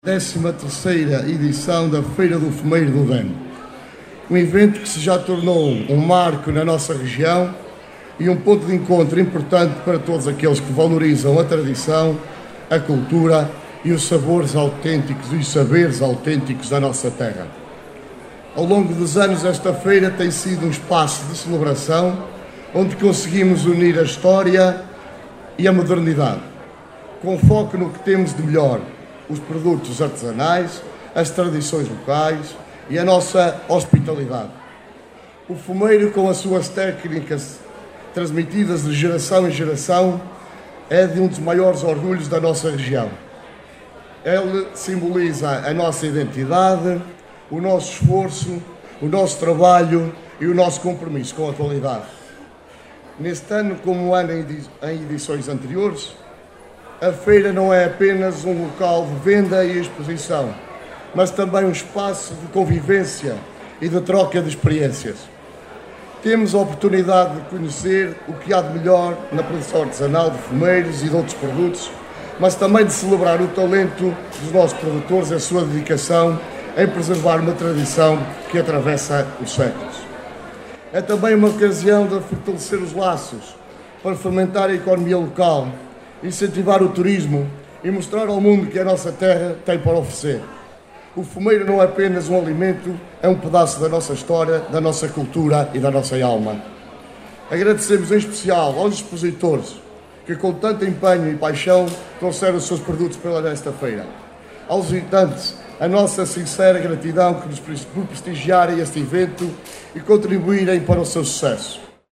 Mário Morgado, Presidente da Junta de Freguesia de Touro, afirmou que este evento já se tornou um marco na região, no sentido da promoção dos produtos artesanais (fumeiro) e tradições locais.